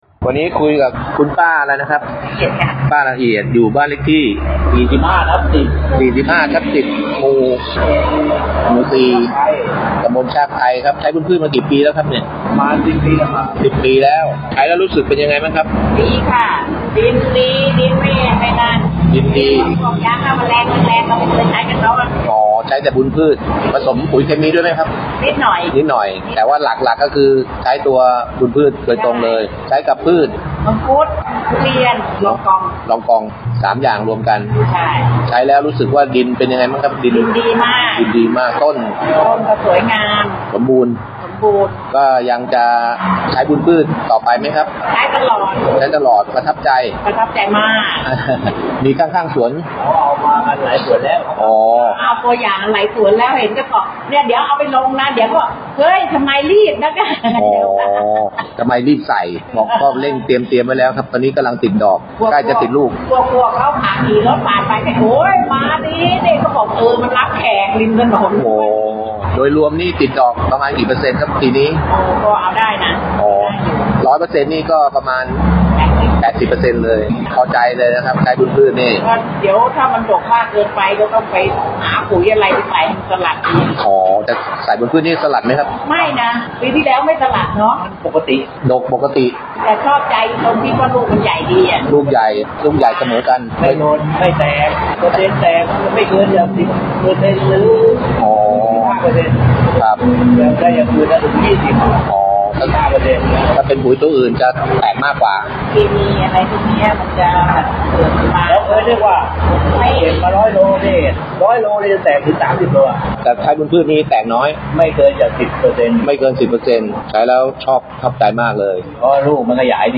เกษตรกร